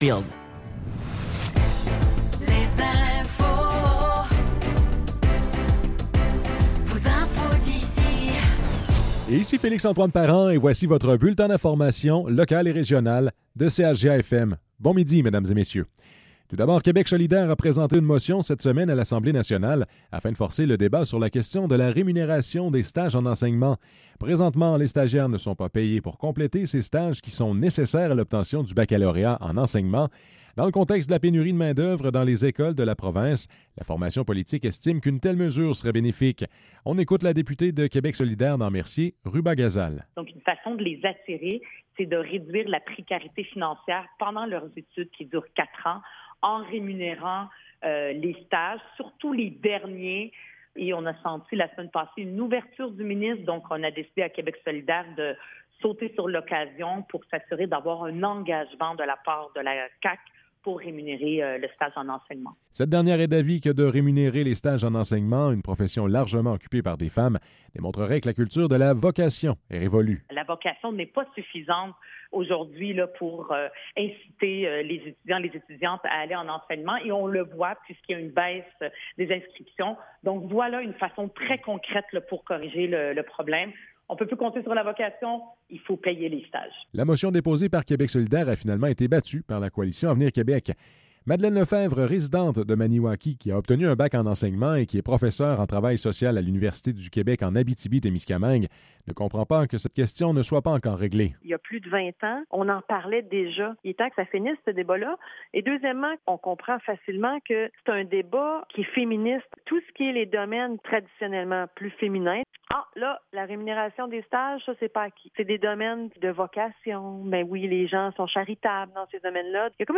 Nouvelles locales - 4 octobre 2024 - 12 h